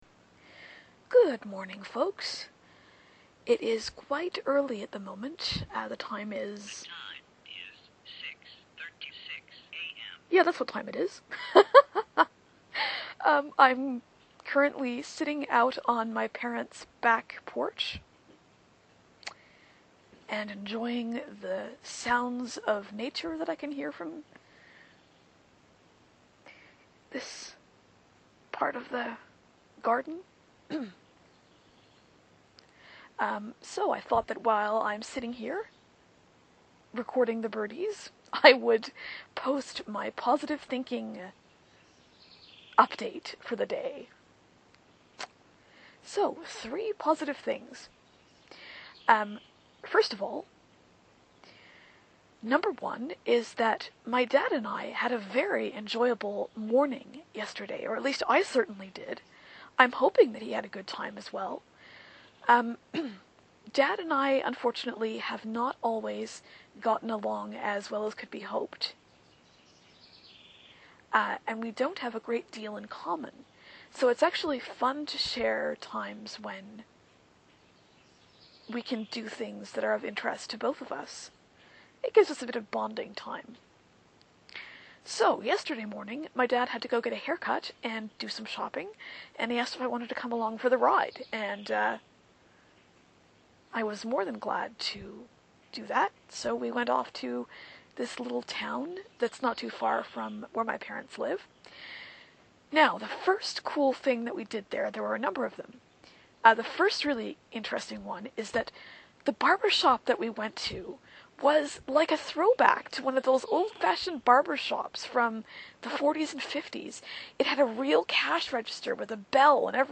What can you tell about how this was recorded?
Recorded in the back garden, complete with birdies and a friendly kitty.